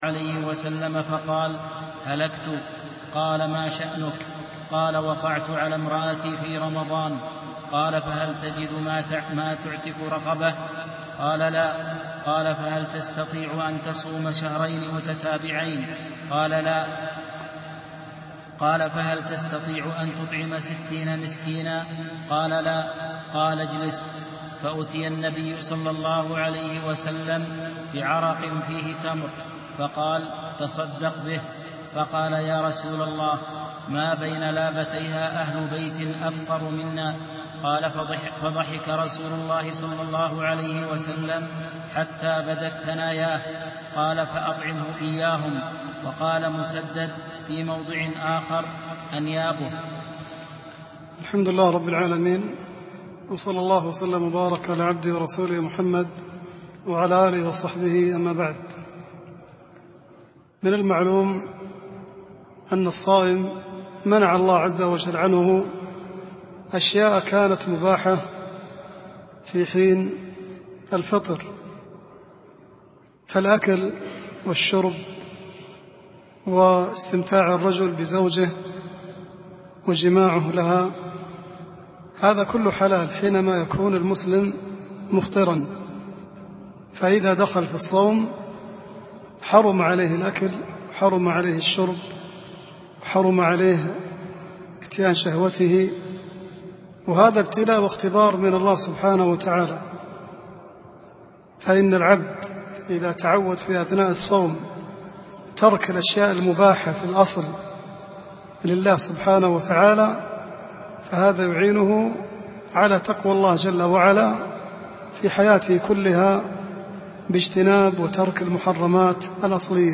Lesson-13-fasting.mp3